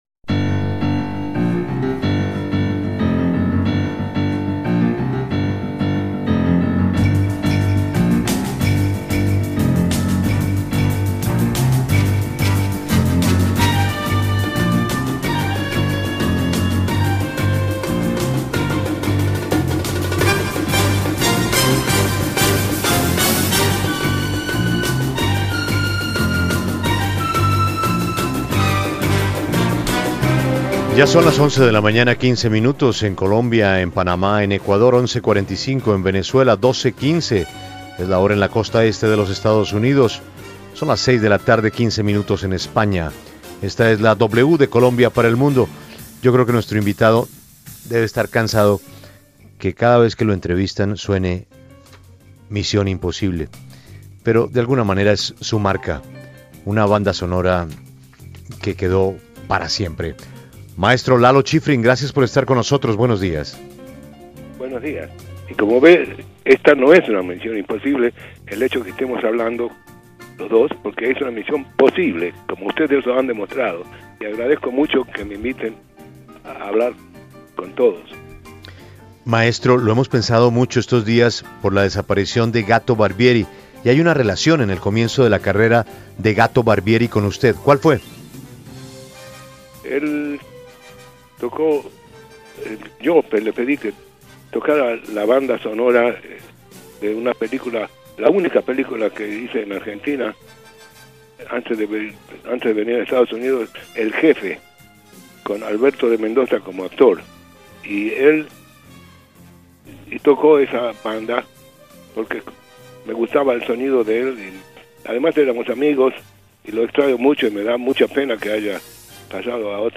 Entrevista con Lalo Schifrin